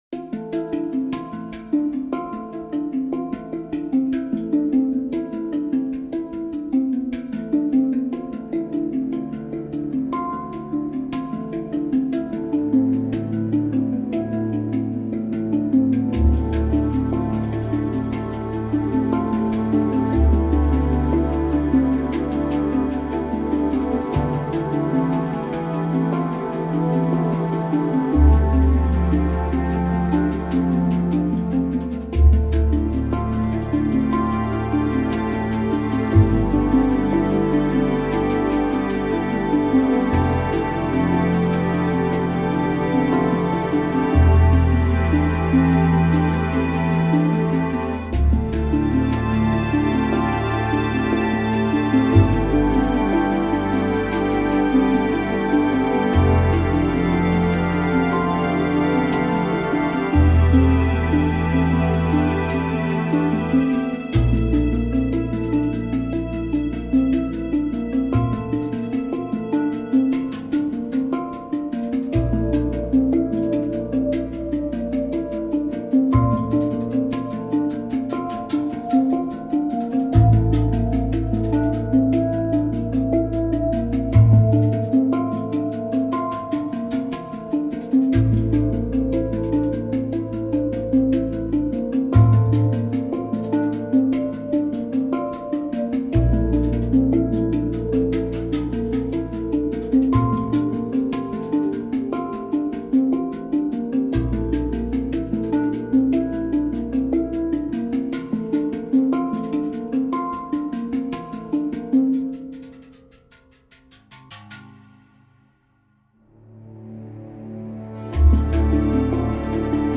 variation for strings